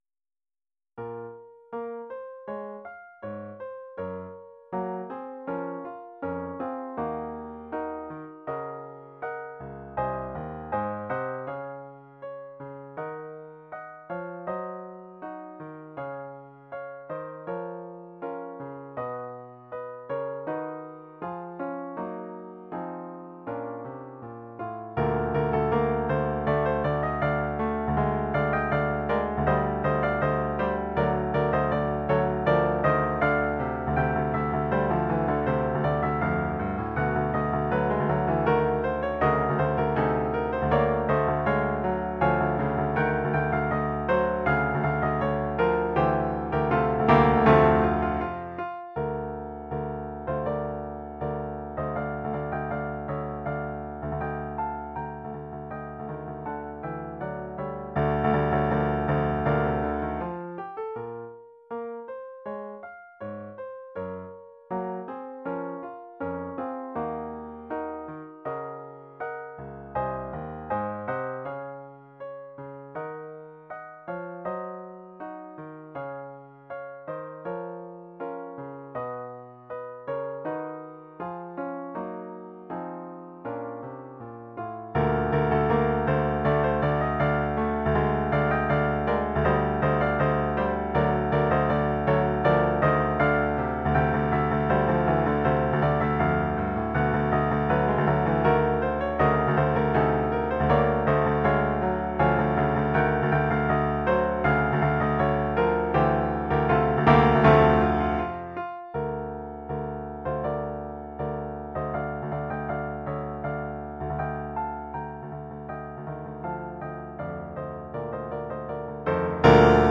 Oeuvre pour caisse claire et piano.